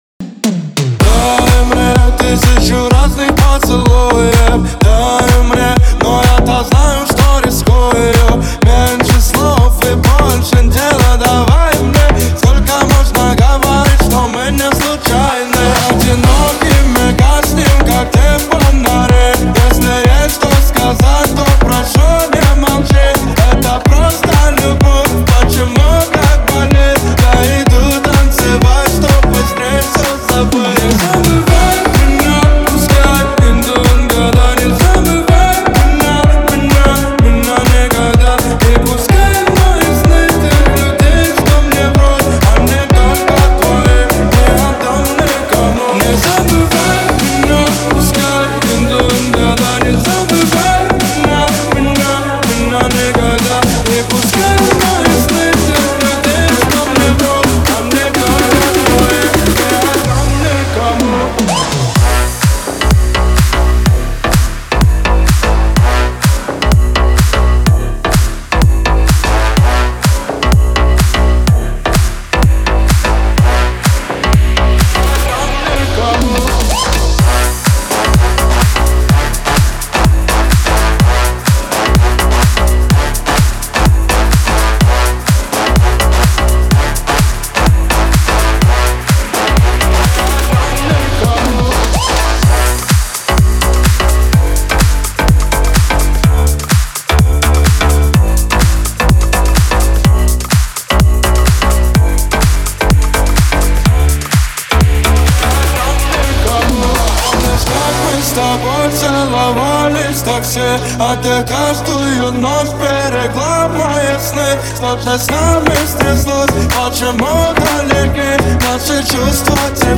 звучит мелодично и эмоционально